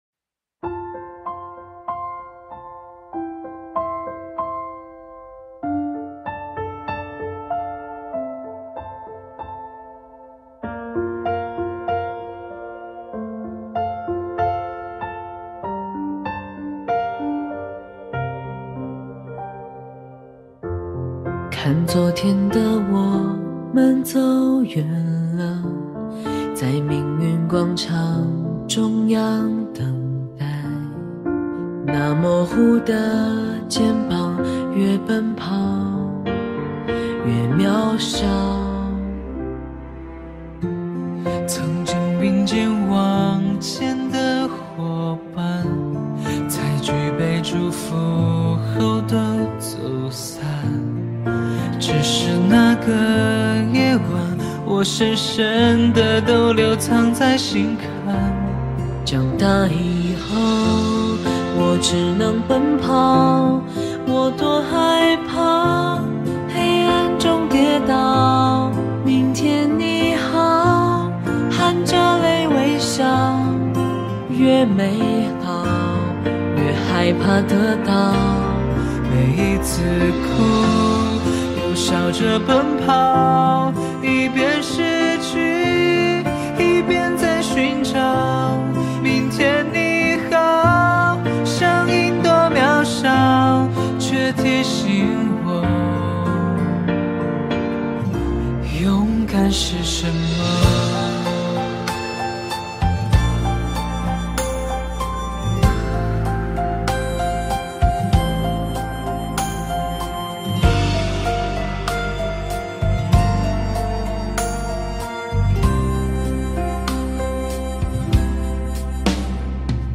(男生版)